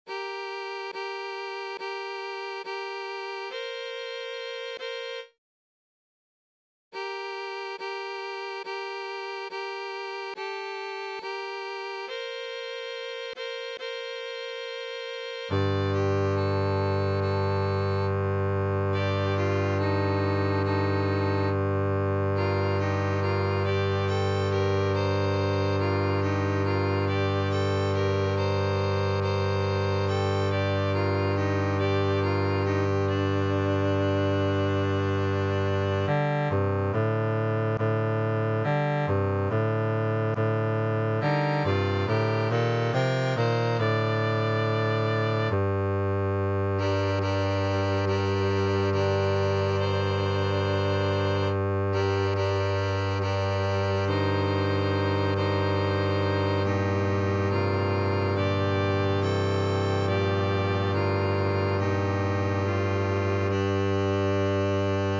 Tria Cantica Sacrea Tutti Tenor 1 Tenor 2 Basse 1 Basse 2 Salve Regina Tutti Tenor 1 Tenor 2 Basse 1 Basse 2 Ave Verum Tutti Tenor 1 Tenor 2 Basse 2 Basse 1 Ave, Mari Stella Partitions